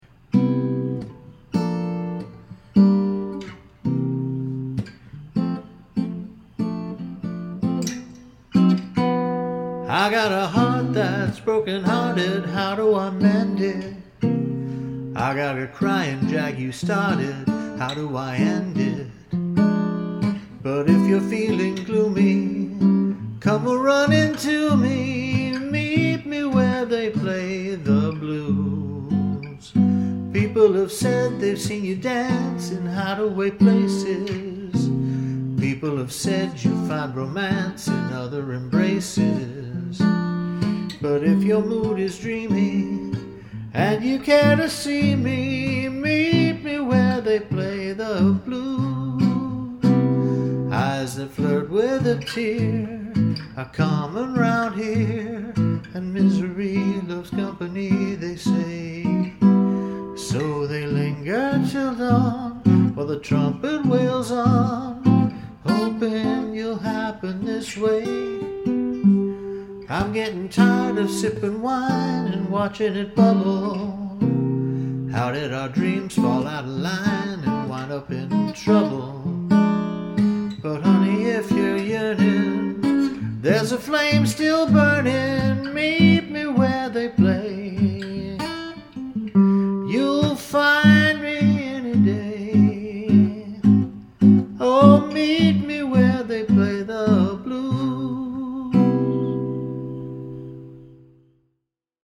jump blues/jazz